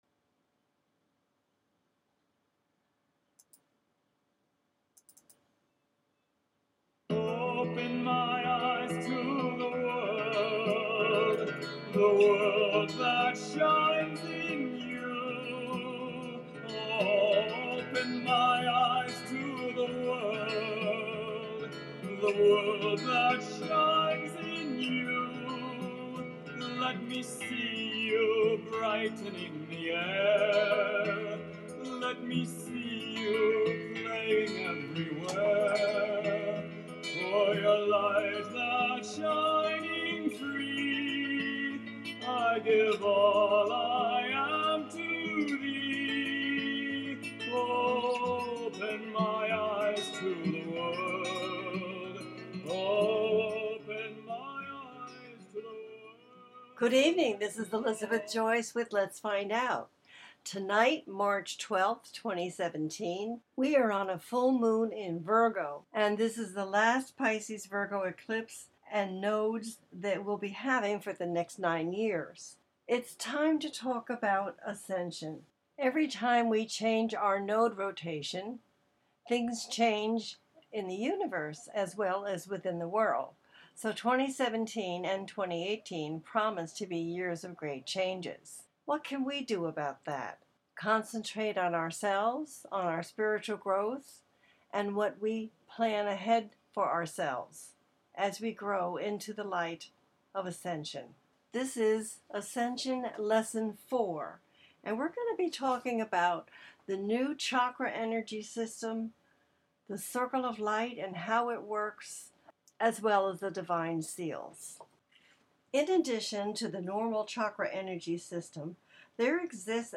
Talk Show Episode
The listener can call in to ask a question on the air.